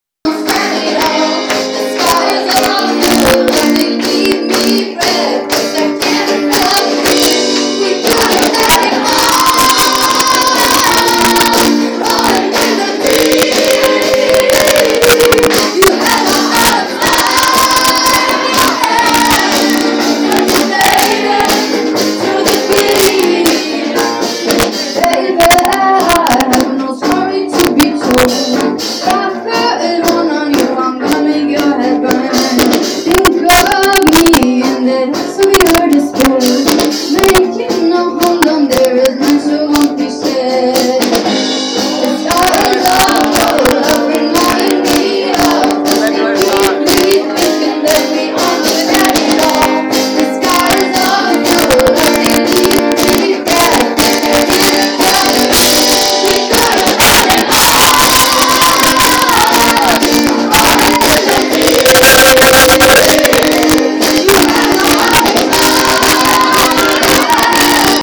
Im Epplehaus fand am Donnerstag wegen schlechtem Wetter spontan das Newcomer Konzert statt, welches ursprünglich als Open Air Konzert am ZOB Tübingen geplant wurde.
Das Newcomer Konzert wurde zu einem ebenfalls multikulturellen Event und die Stimmung war euphorisch, entspannt.
Zugaben wurden eingefordert und das vorwiegend jugendliche Publikum tanzte und sang ausgelassen mit. Die Fanbase spendierete begeisterten Applaus für die regionalen Künstler_innen.
Alle singen mit....on Stage: 26.9.24, Maybe Yesterday